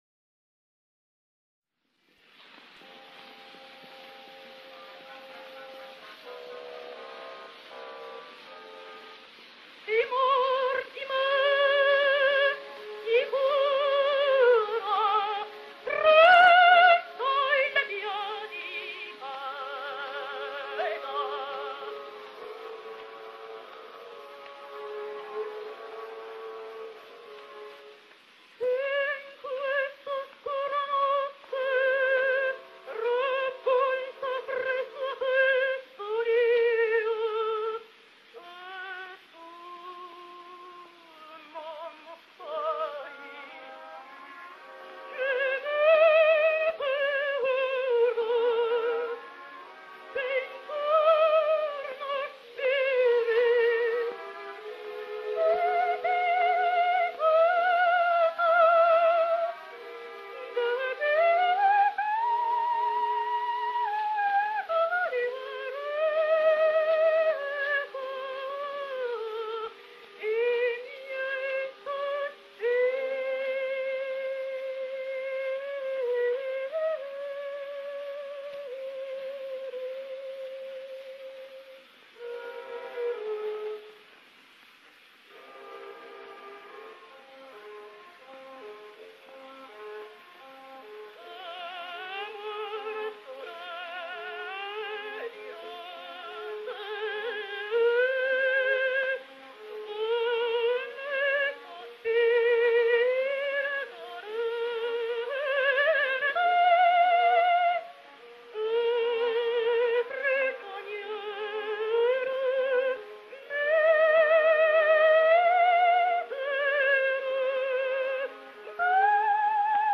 soprano  •  mezzosoprano  •  contralto